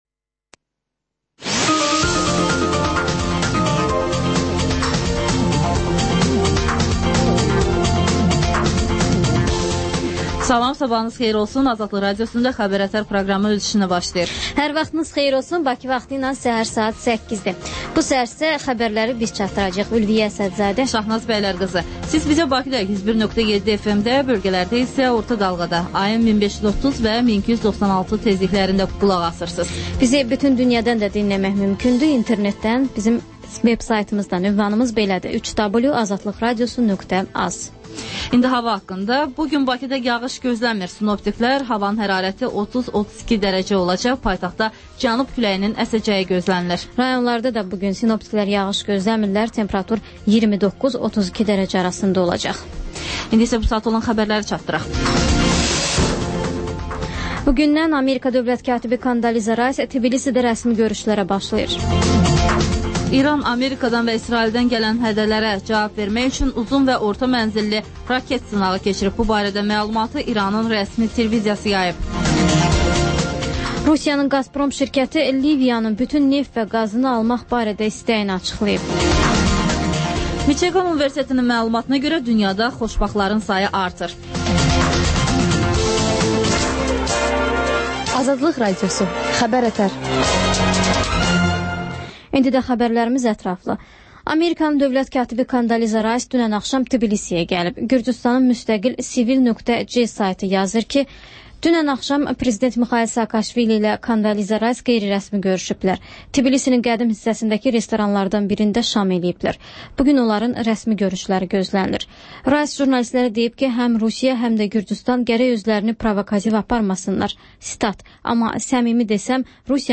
Xəbərlər, müsahibələr